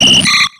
Cri de Poissirène dans Pokémon X et Y.